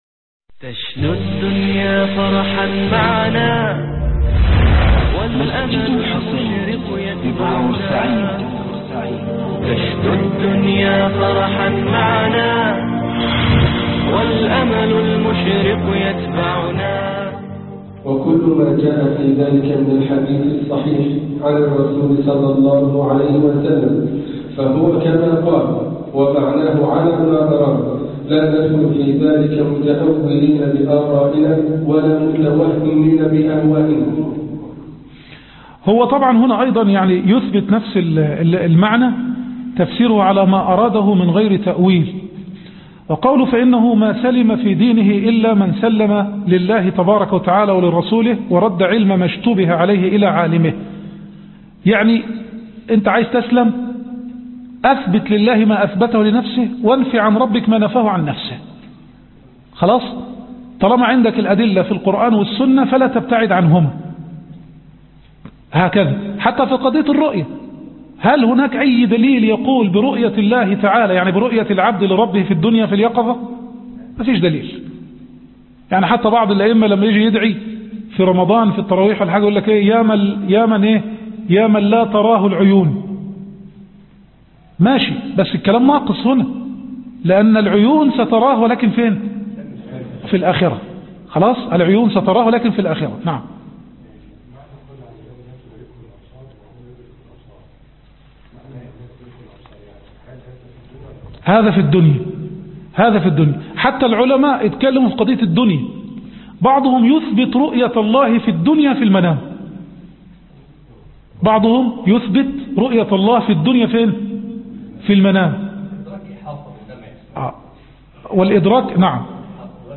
دورة الدرر البهية فى تيسير العقيدة الطحاوية ج4 - مسجد الحسين